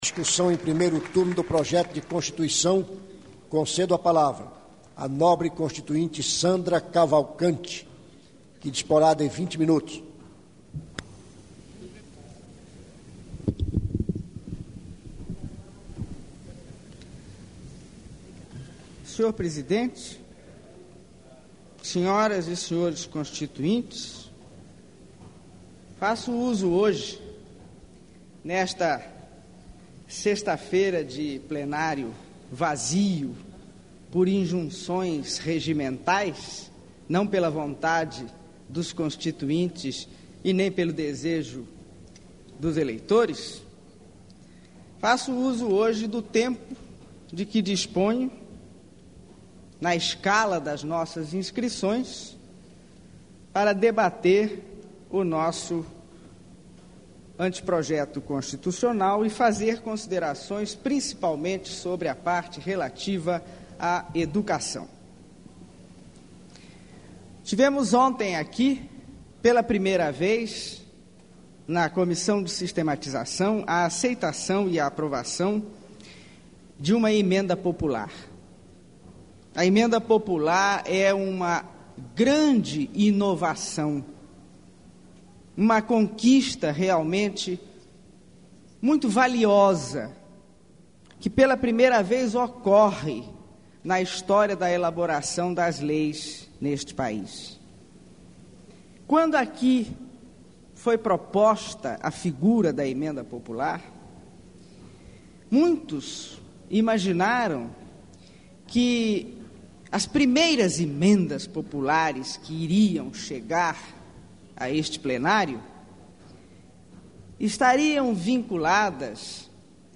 - Discurso pronunciado em 24 de julho de 1987 – Subvenção a instituições de educação com fins lucrativosDiscussão, em primeiro turno do Projeto de Constituição; Considerações sobre emenda, de sua autoria, que proíbe o Poder Público de subvencionar instituições de educação com fins lucrativos; necessidade das crianças serem educadas dentro dos valores da família brasileira, entre os quais o valor religioso; emenda popular sobre educação, aprovada na Comissão de Sistematização.